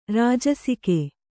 ♪ rājasike